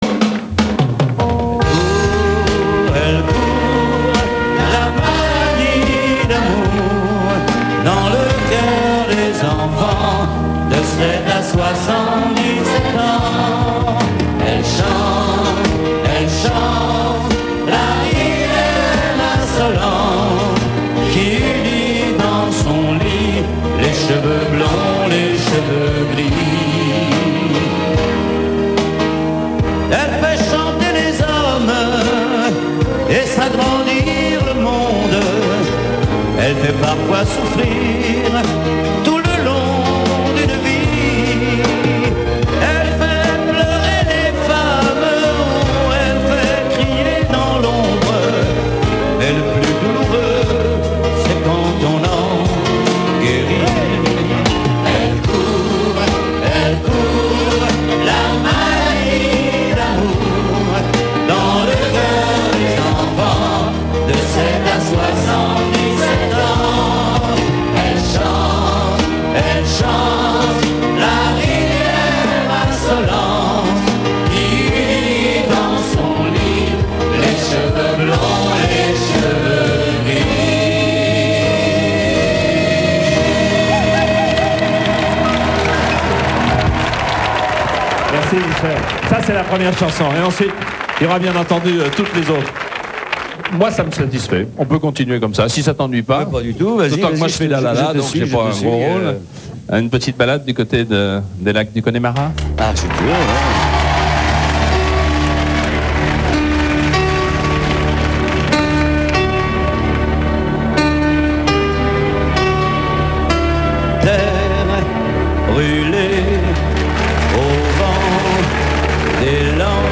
TV-Radio en direct (hors concerts)